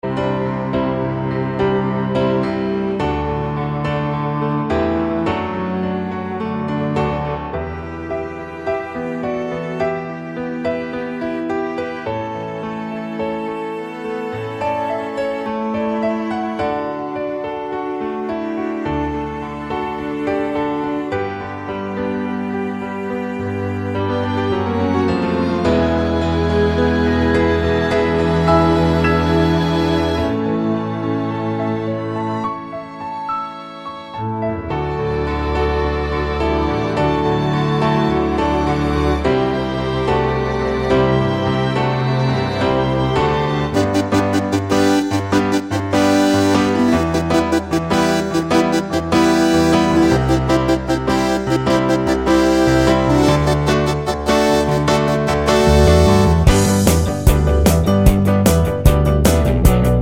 Velocity Mix Pop (1970s) 4:14 Buy £1.50